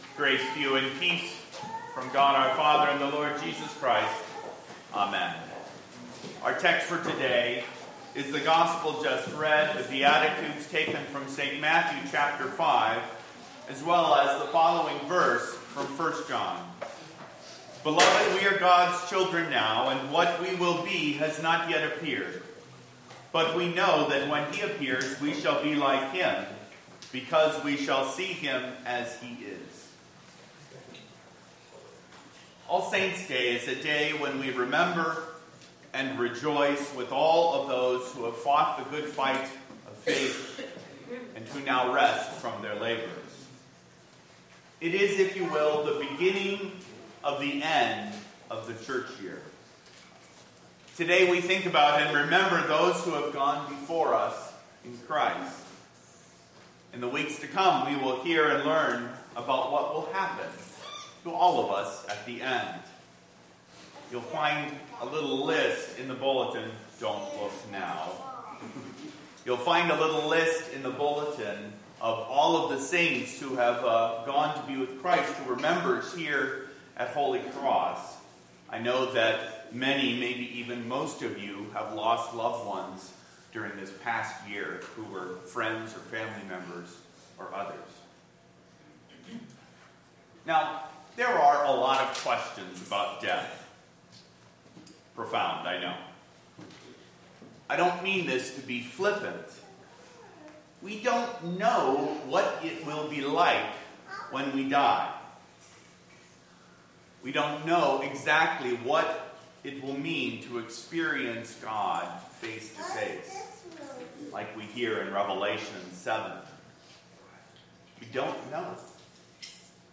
sermon11-03-13.mp3